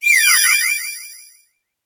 Cri de Chapotus dans Pokémon HOME.